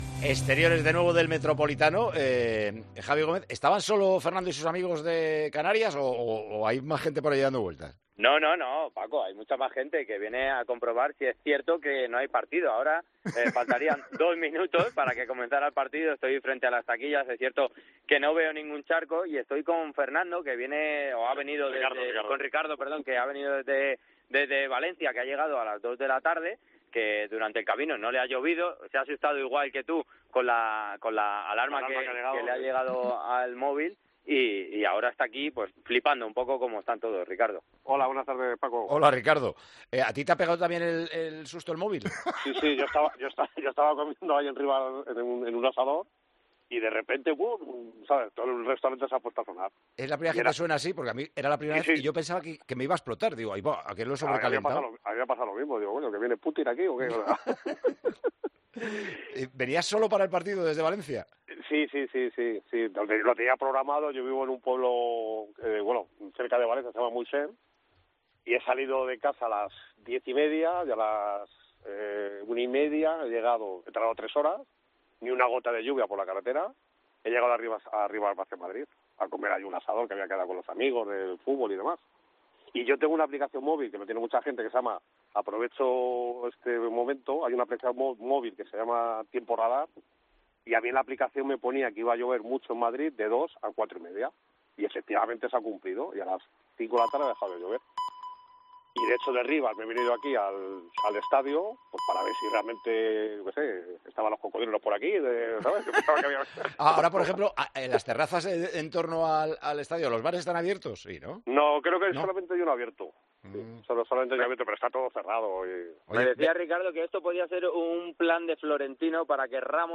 Escucha el fragmento de Tiempo de Juego con varios aficionados que iban a asistir al partido entre Atlético de Madrid y Sevilla en el Civitas Metropolitano este domingo.